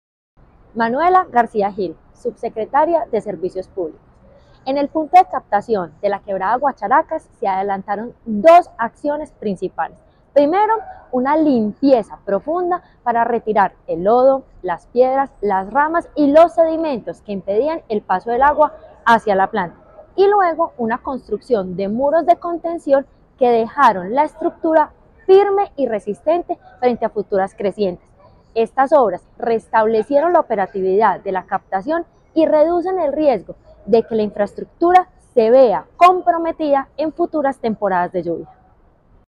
Declaraciones de la subsecretaria de Servicios Públicos, Manuela García Gil